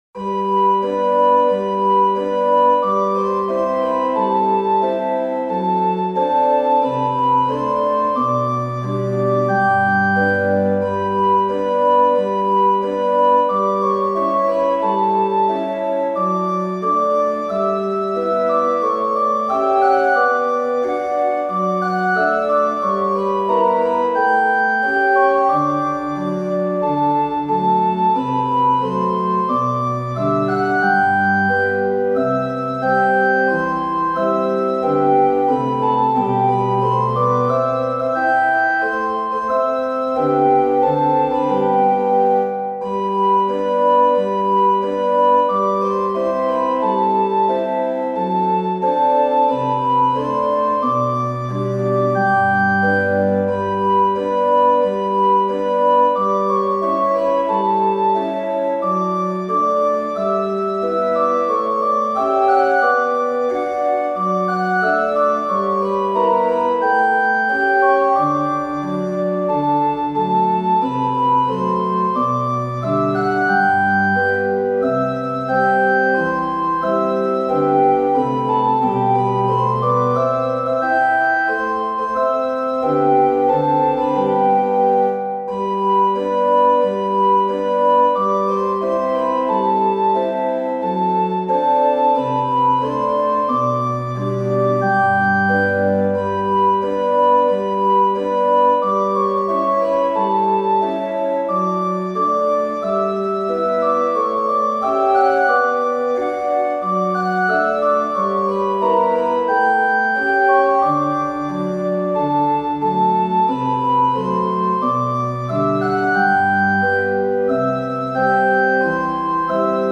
＜オルガン＞
♪オルガンフルートという丸みのある綺麗な音色のパイプオルガンを使用しています。